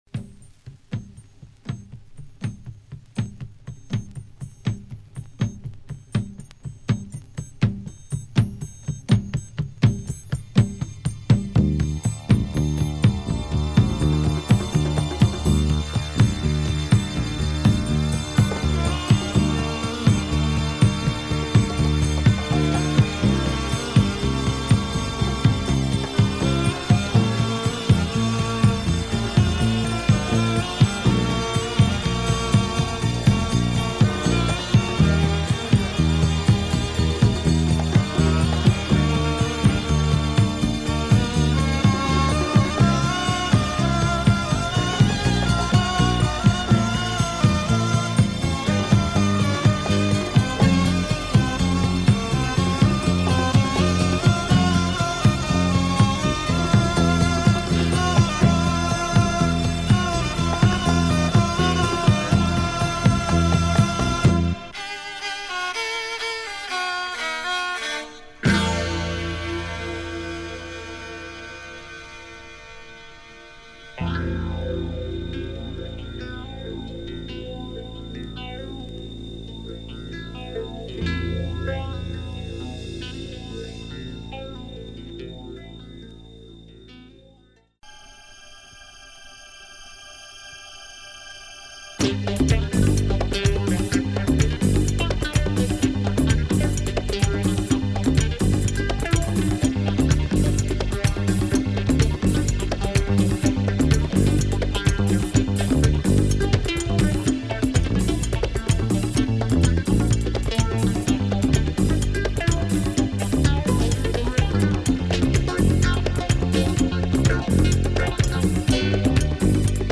keyboards
drums